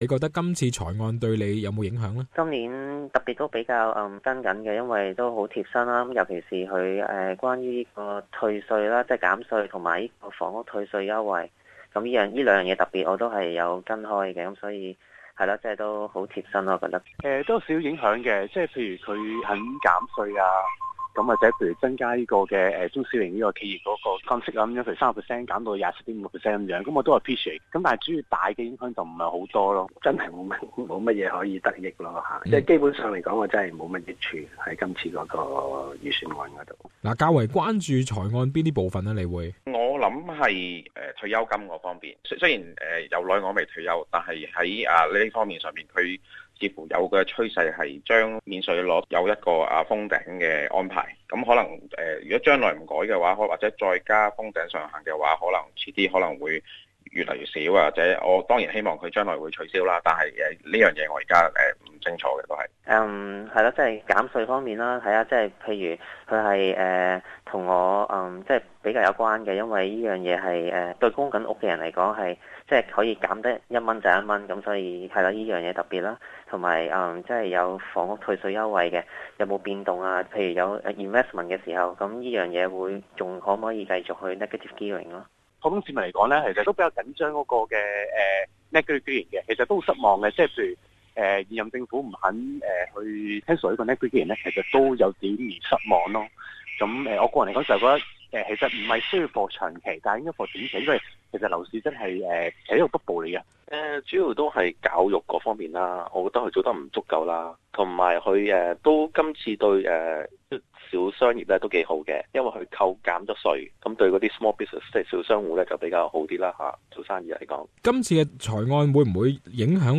民意訪問:2016財案對你有何影響?